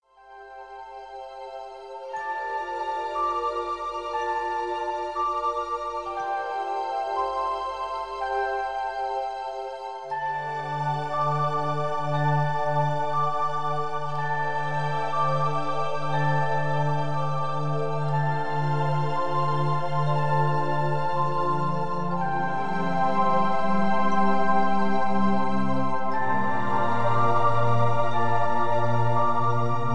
Hier ist die Musik OHNE Sprache.